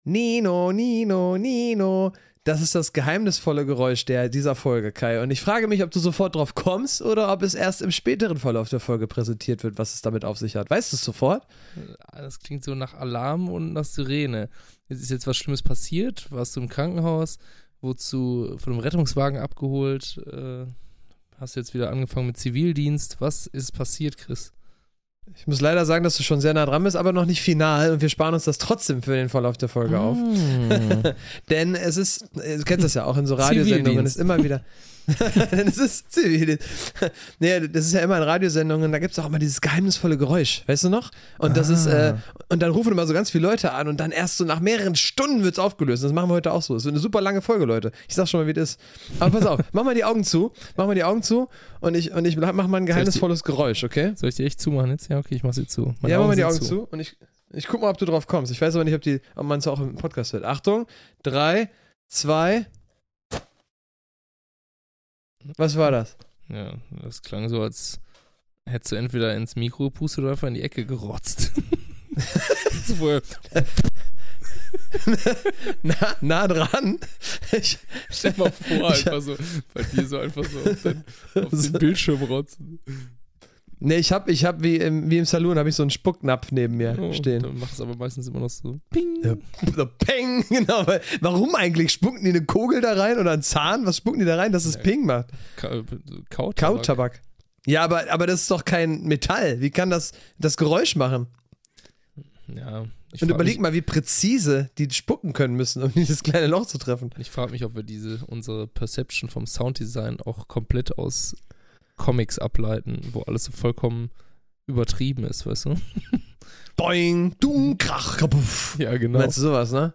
Das geheimnisvolle Geräusch „NINONINO“.